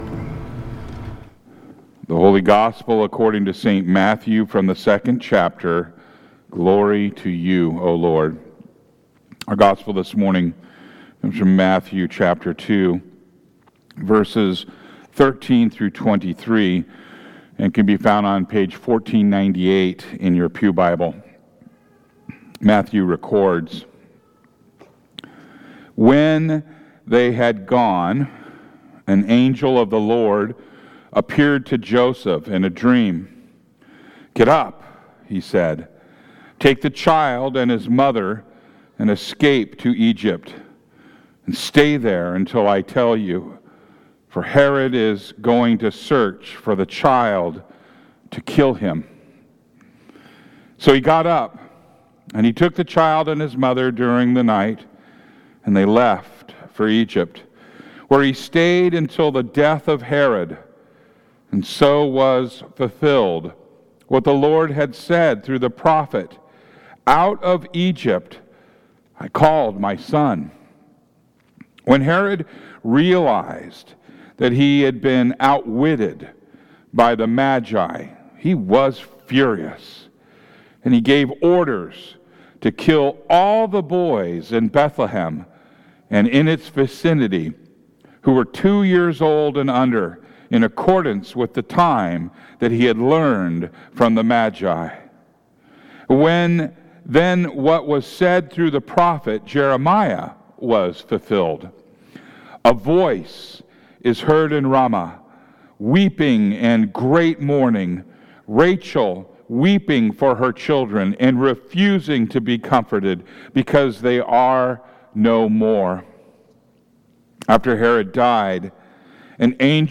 No signup or install needed. 28 DECEMBER 2025 Gospel and Sermon. 28 DECEMBER 2025 Service.
Worship Services